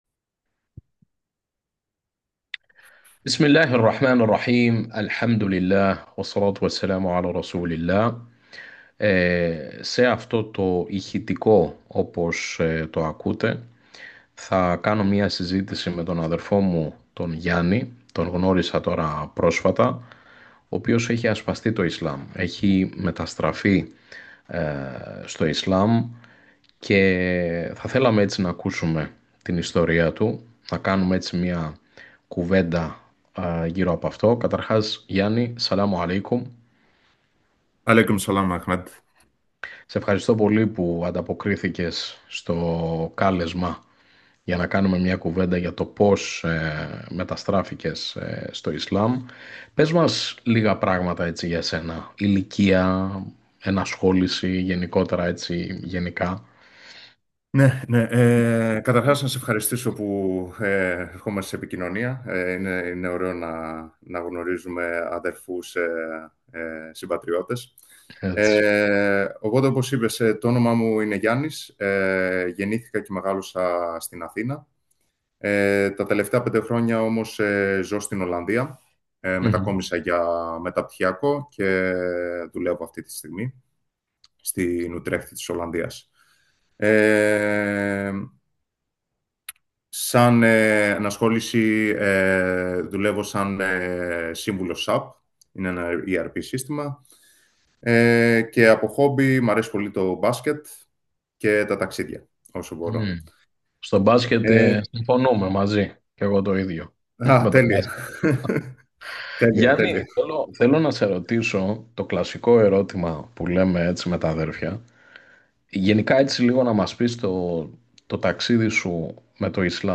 Ακούστε το ηχητικό της συζήτησης